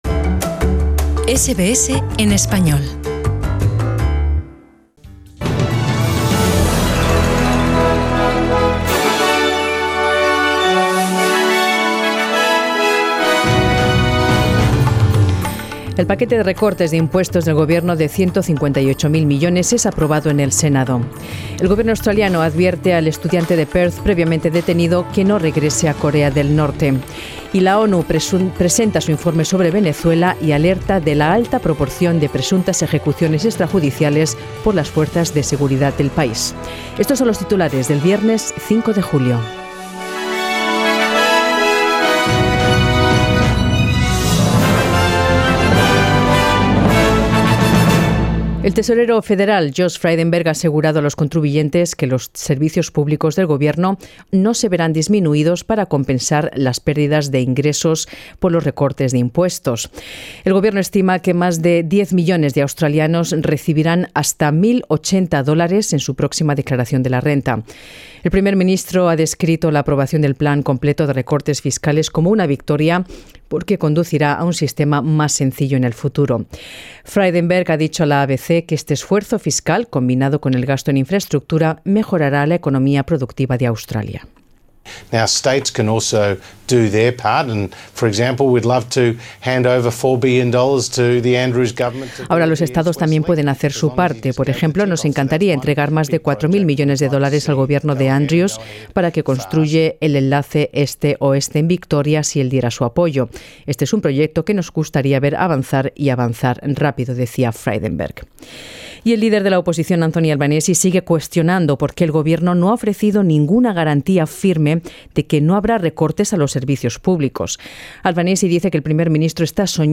Noticias SBS Spanish | 5 julio 2019